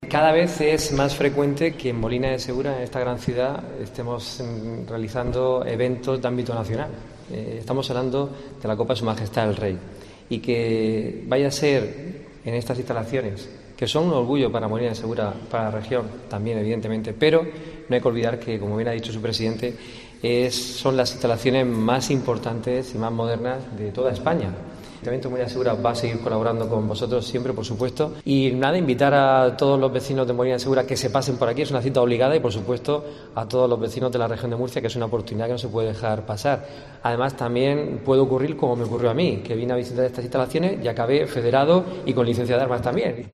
José Ángel Alfonso, alcalde de Molina de Segura